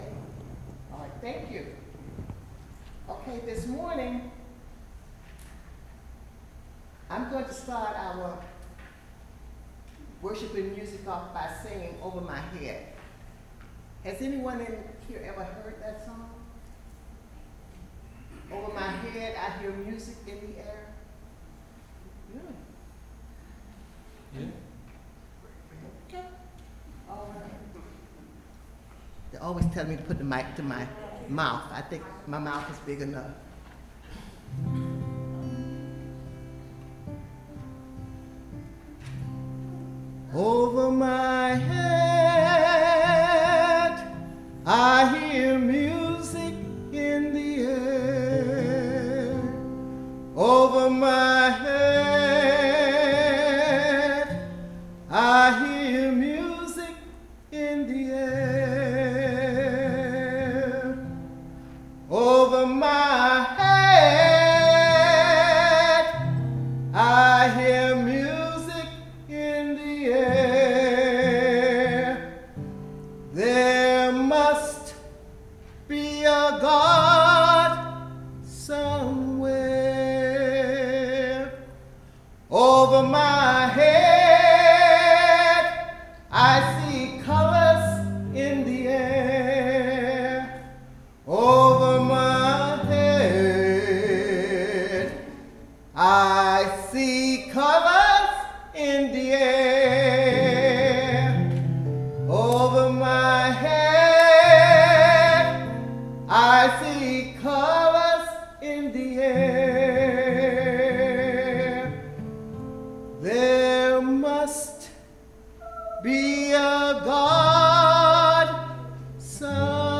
Online Sunday Service
Sermon